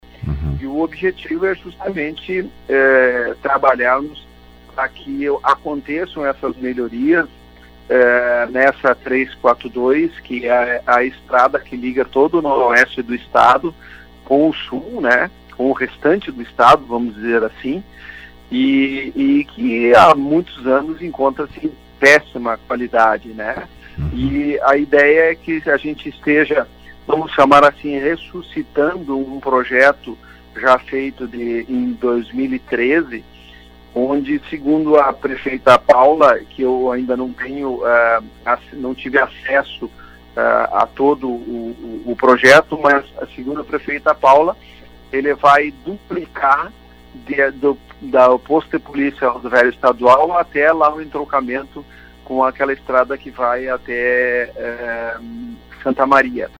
Falando hoje no Fatorama, ele explicou os encaminhamentos realizados na oportunidade para o referido projeto.